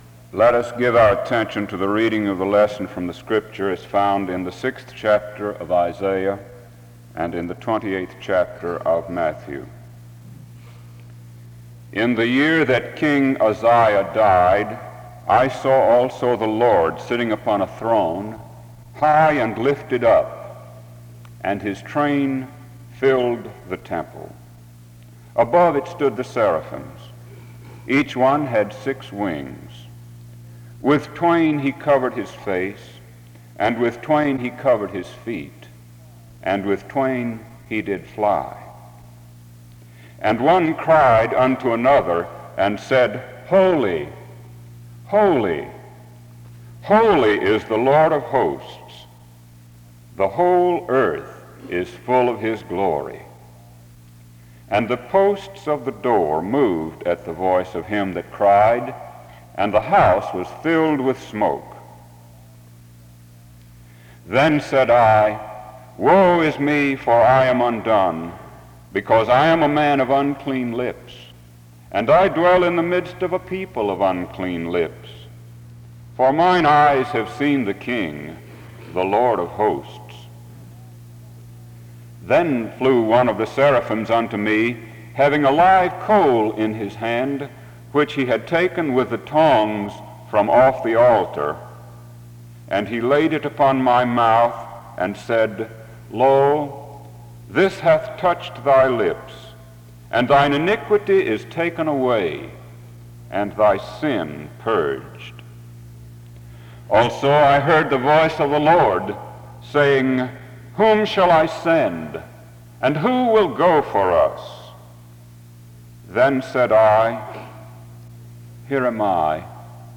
The service begins with a scripture reading of selected passages from Isaiah 6 and Matthew 28 from 0:00-2:36. A prayer is offered from 2:39-4:58. An introduction to the speaker is given from 5:04-7:53.
Music plays from 46:28-46:55. A closing prayer is offered from 46:59-47:28.
SEBTS Chapel and Special Event Recordings SEBTS Chapel and Special Event Recordings